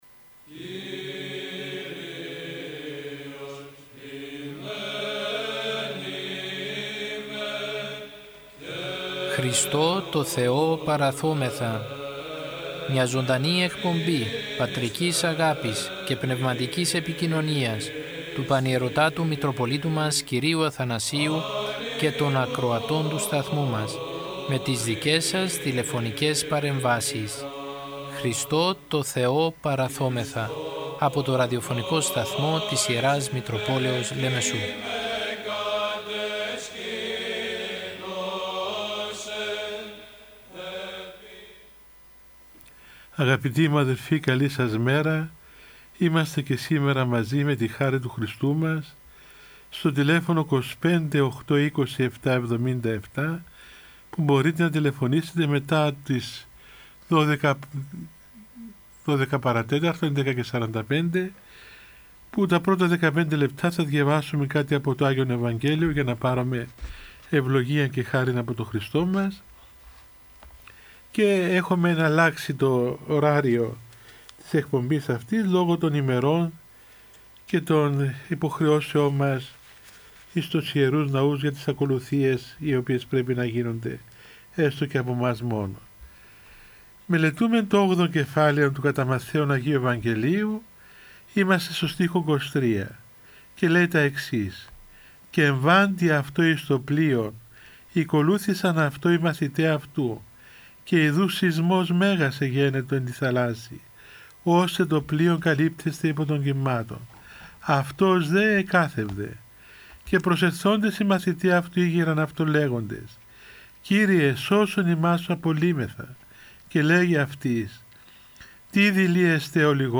Ο Πανιερώτατος Μητροπολίτης Λεμεσού κ. Αθανάσιος μέσω του ραδιοφωνικού σταθμού της Μητροπόλεως του καθημερινά επικοινωνεί με το ποίμνιο με τηλεφωνικές συνδέσεις και απευθύνει παρηγορητικό λόγο για την δοκιμασία που περνάει ο Ορθόδοξος λαός για την πανδημία με κλειστές εκκλησίες και απαγορεύσεις.